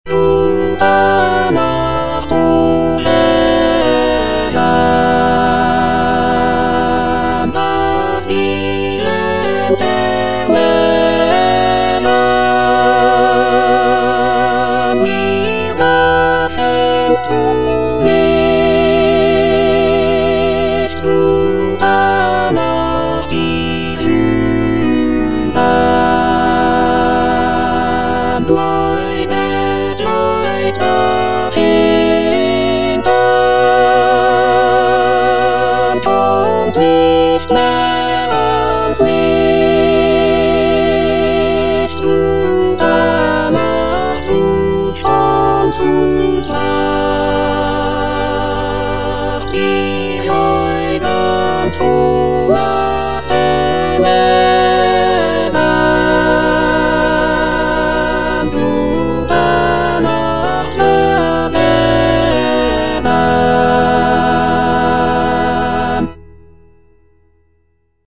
Cantata
Alto (Emphasised voice and other voices) Ads stop